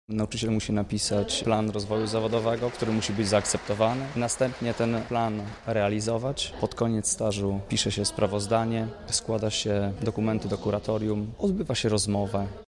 Mówi jeden z dyplomowanych nauczycieli, którzy podczas rozmowy potwierdzającej kwalifikacje zawodowe uzyskali najwyższą ocenę.